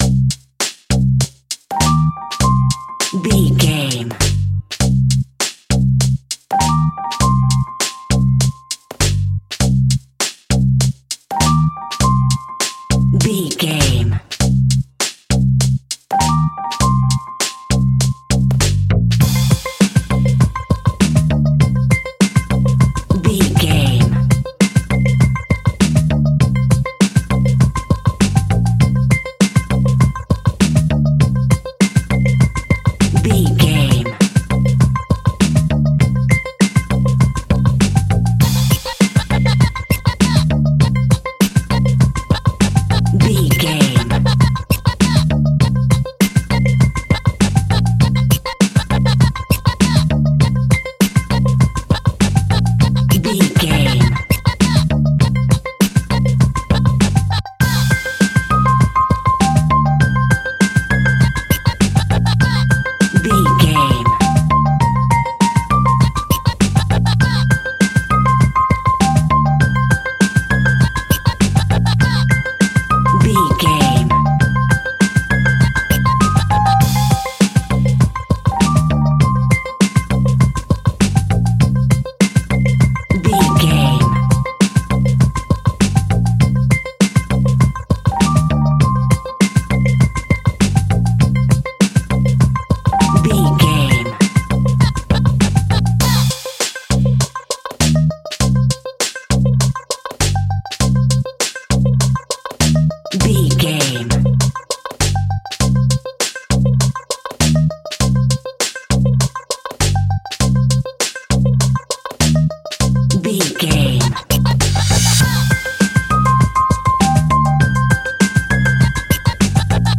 Hip Hop Vibes Music.
Aeolian/Minor
hip hop instrumentals
chilled
laid back
groove
hip hop drums
hip hop synths
piano
hip hop pads